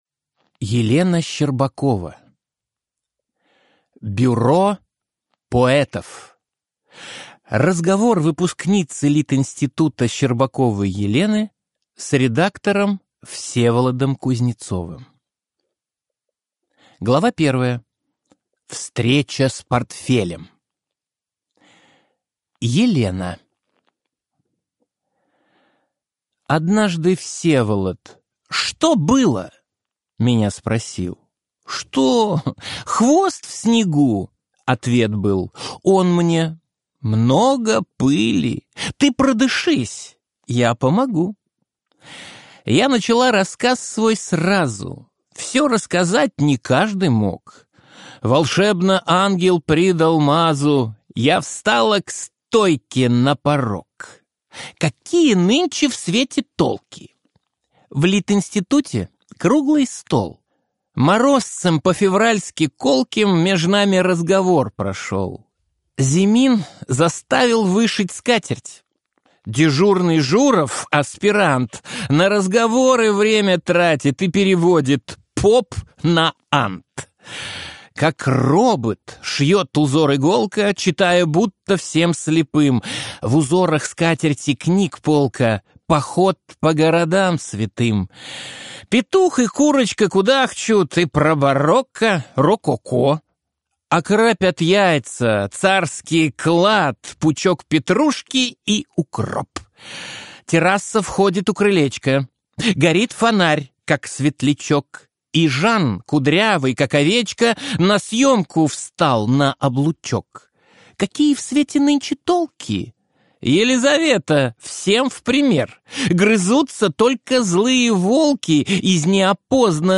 Аудиокнига Бюро поэтов | Библиотека аудиокниг